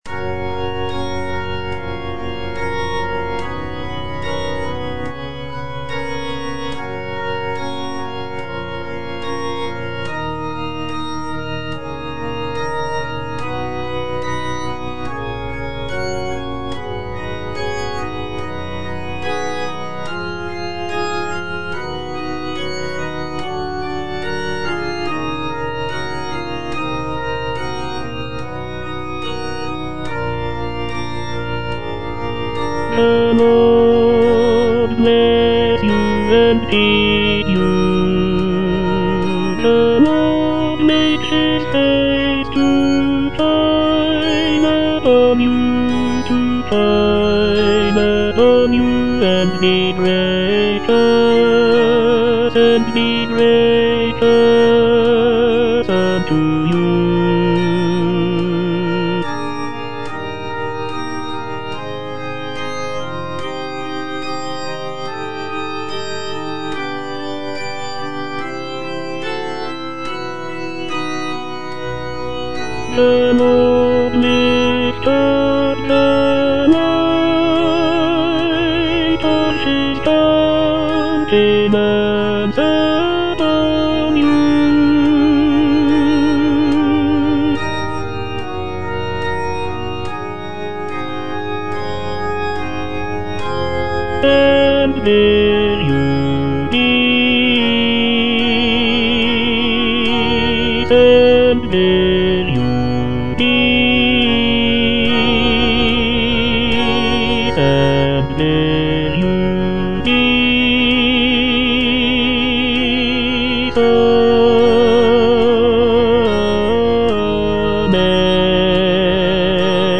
Tenor (Voice with metronome)
choral benediction